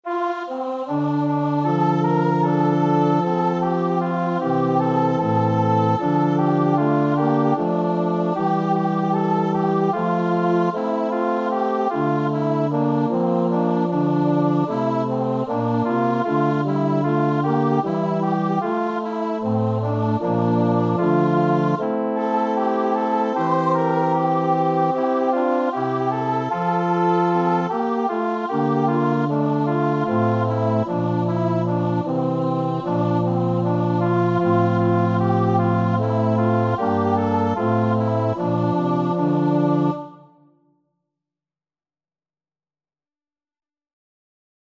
Free Sheet music for Voice
Traditional Music of unknown author.
4/4 (View more 4/4 Music)
C major (Sounding Pitch) (View more C major Music for Voice )
M.M. =c.152
Christian (View more Christian Voice Music)